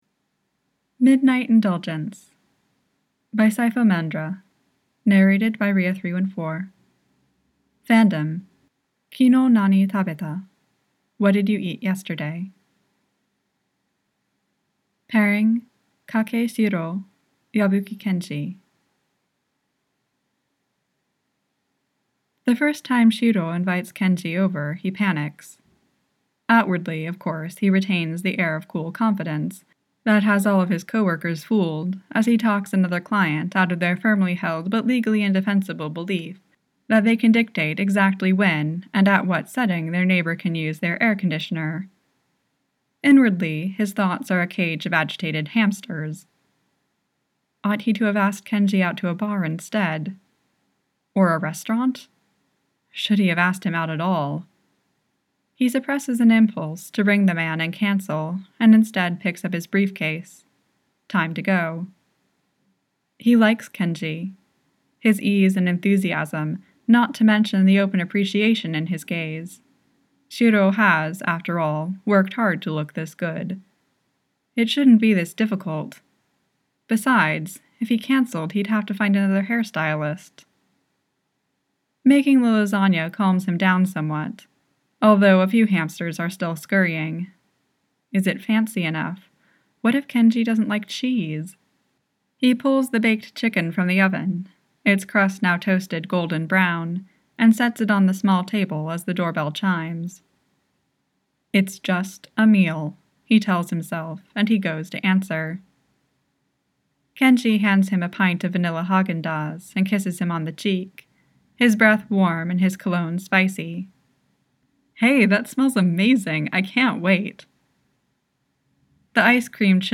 with music: download mp3: here (r-click or press, and 'save link') [32 MB, 00:22:00]